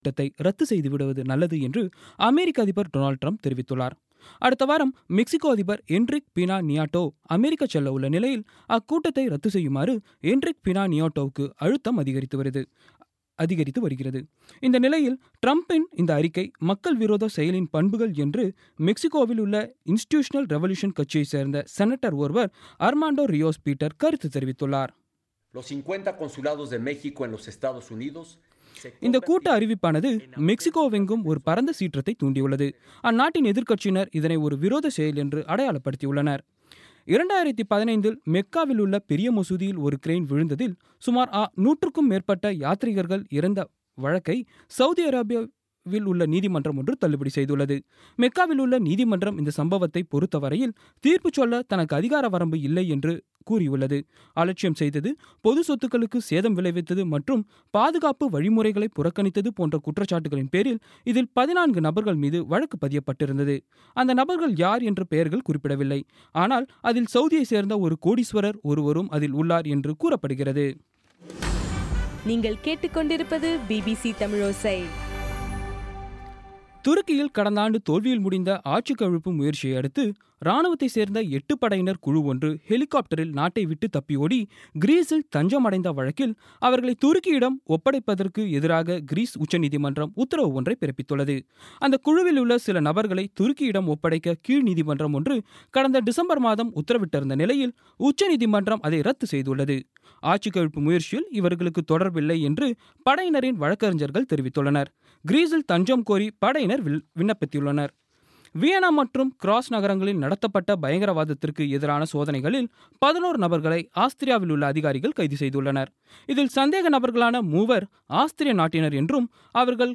பிபிசி தமிழோசை செய்தியறிக்கை (26/01/2017)